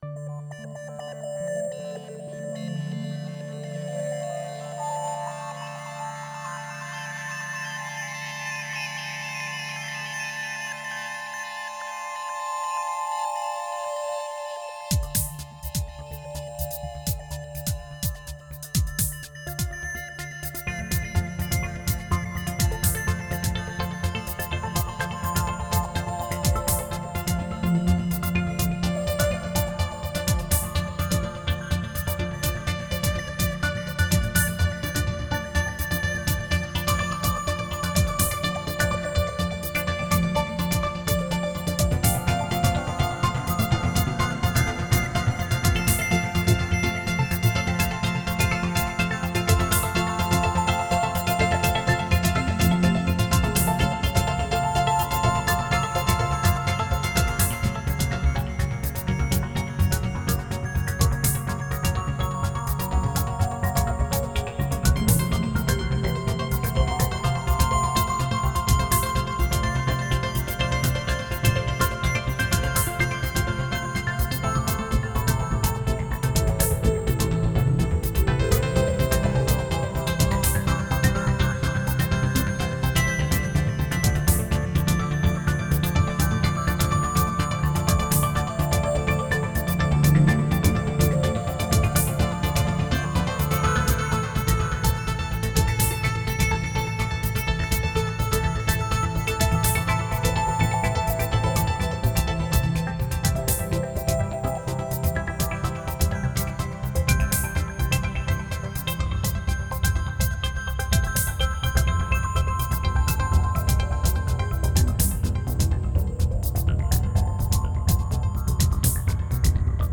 Мистическая музыка Духовная музыка Медитативная музыка